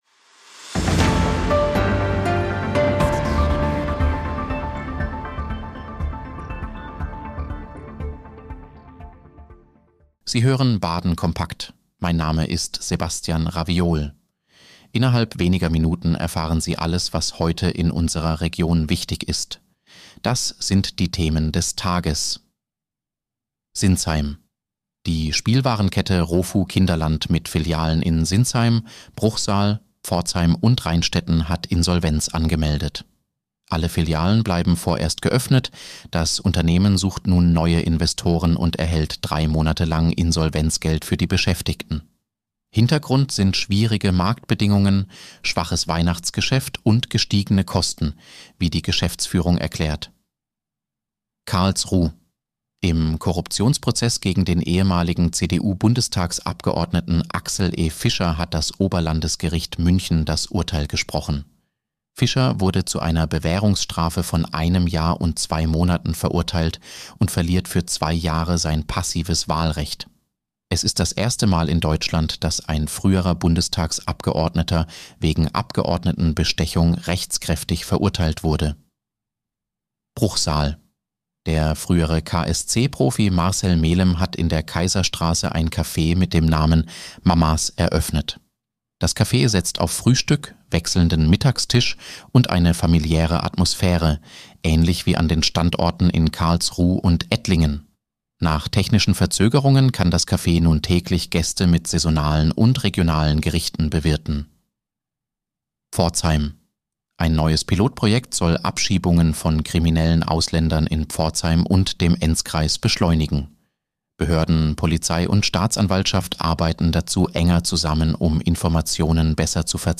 Nachrichtenüberblick Donnerstag, 22. Januar 2026